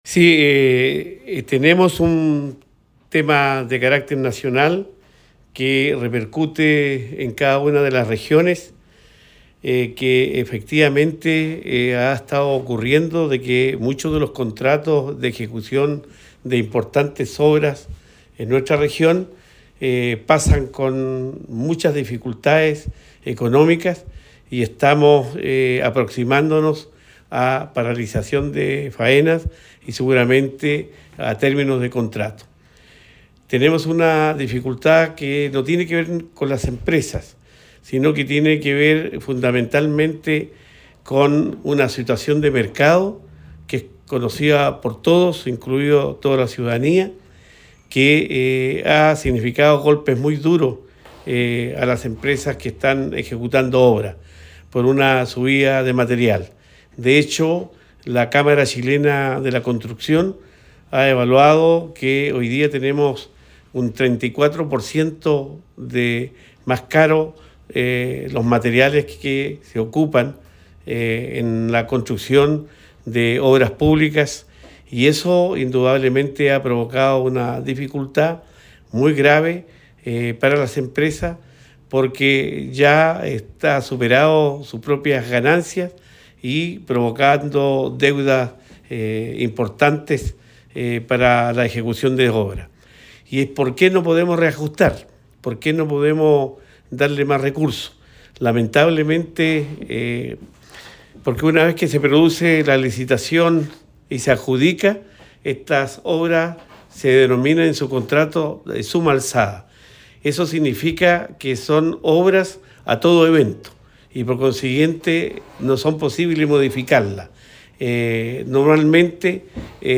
Cuña_Gobernador-Regional_oficio-Presidente-BORIC-1.mp3